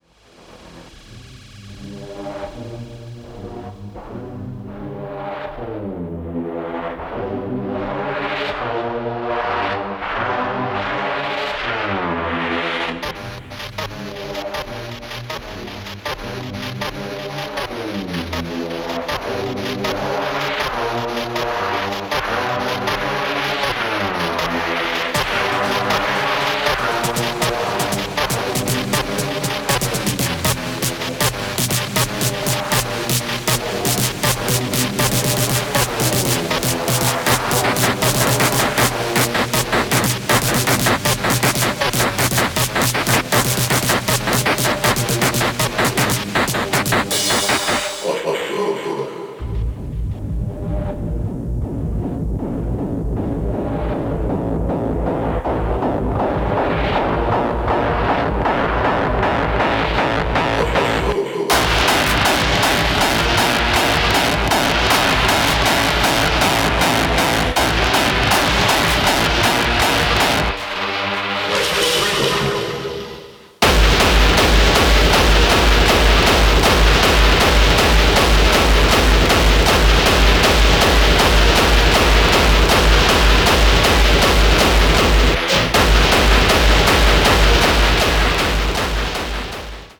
Diverse, personal, tormented and yet dancefloor compatible